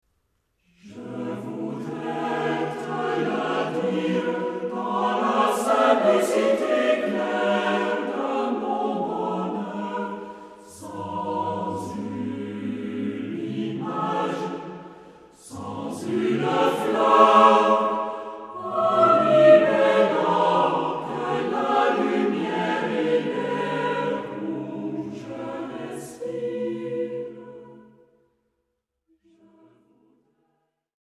SATB (4 voix mixtes).
contemporain ; Profane ; Cycle
mélodieux ; mystique ; contemplatif